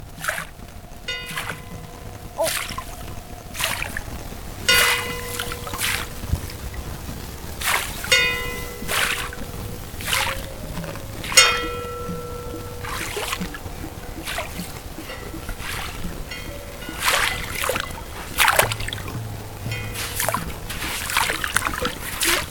모래+카우벨.mp3